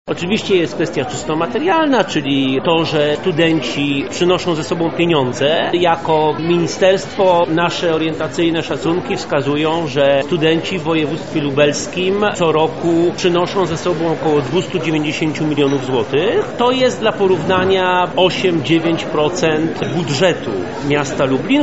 Konferencja pod tytułem „Studenci zagraniczni w Lublinie – potencjał doświadczeń i nowe perspektywy”, która odbyła się 5 grudnia na Wydziale Politologii i Dziennikarstwa UMCS była polem do dyskusji na temat wad i zalet przyjmowania obcokrajowców przez polskie uczelnie.
Andrzej Szeptycki – mówi Andrzej Szeptycki, Podsekretarz Stanu w Ministerstwie Nauki i Szkolnictwa Wyższego.